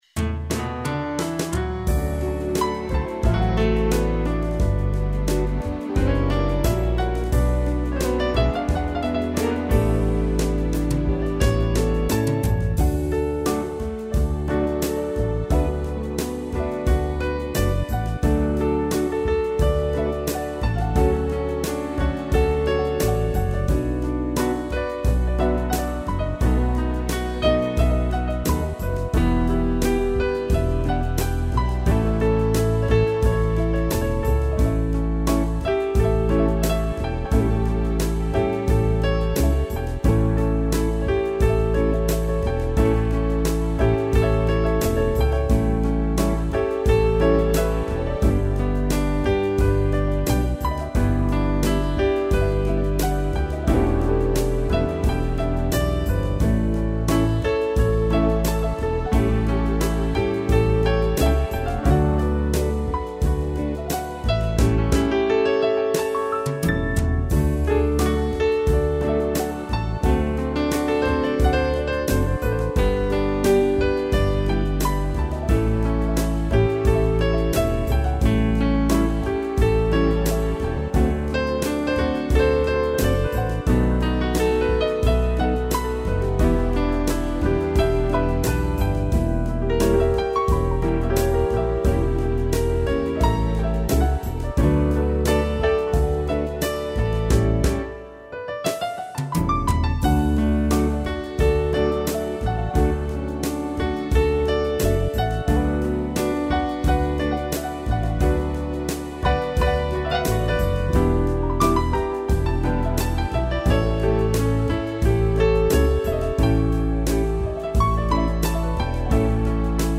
piano
instrumental